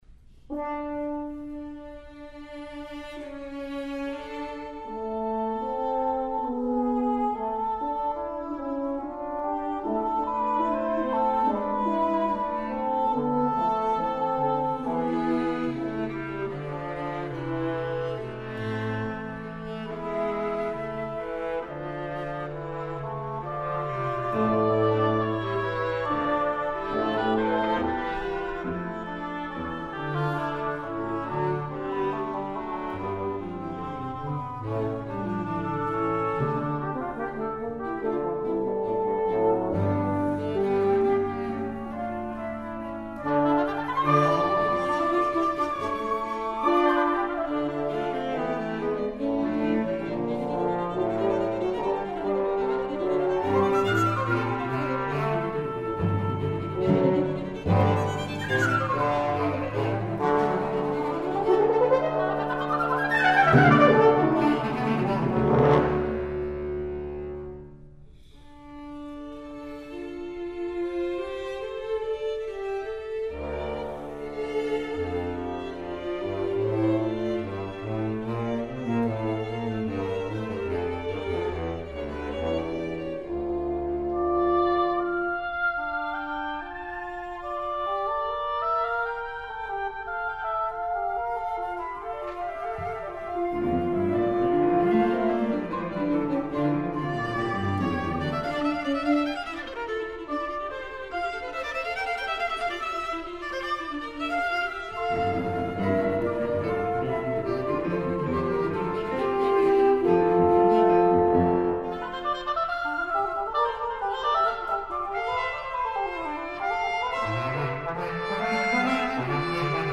for 14 instruments